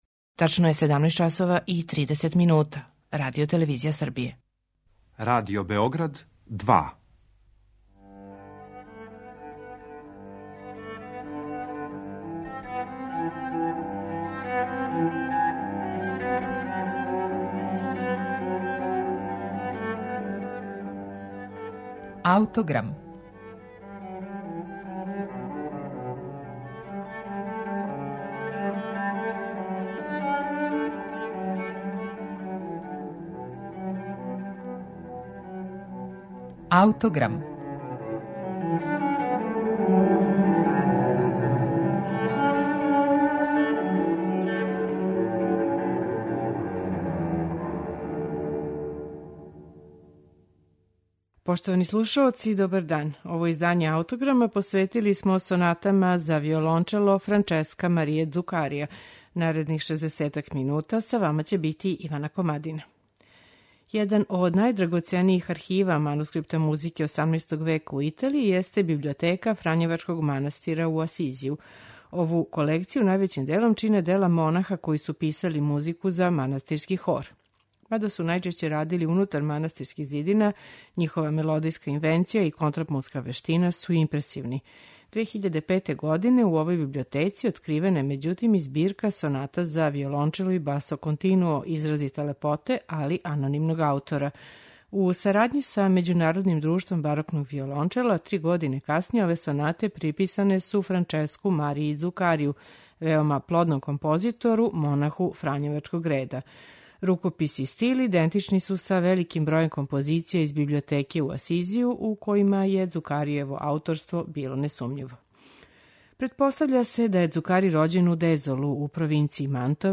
Емисију смо посветили Сонатама за виолончело, Фраческа Цукарија
У овој библиотеци, 2005. године откривена је и збирка соната за виолончело и басо континуо изразите лепоте, али непознатог аутора.
Већина соната писана је у четвороставачној форми и следе облик такозване "сонате да кјеза".
Јуна 2010. године остварили су га чланови ансамбла Musica Perduta на оригиналним барокним инструментима.